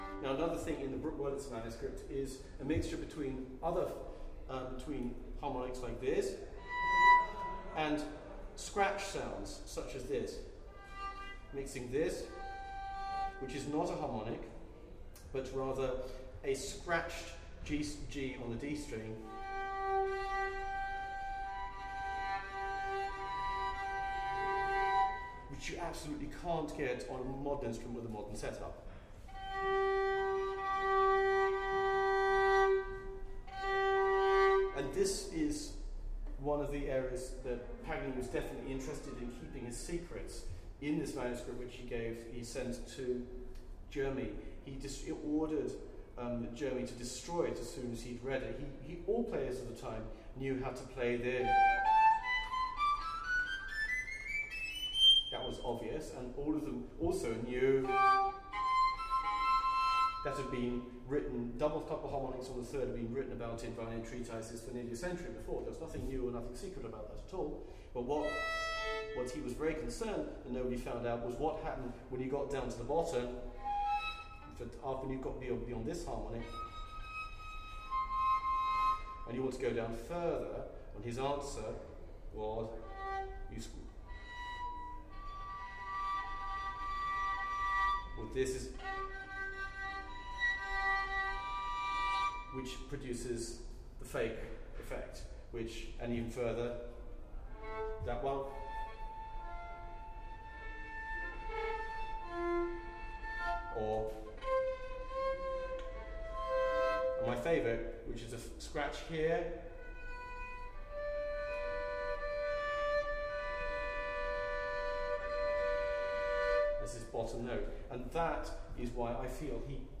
Now another notable feature of the Bruck-Wurlitzer manuscript is a mixture between fingered harmonics  and ‘scratch sounds’ which is not a harmonic, but rather a ‘scratched’ g’ on the D string.
His answer was this mixture of harmonic and scratch, which produces the ‘fake’ effect.
Paganini-and-Scratch-tones.mp3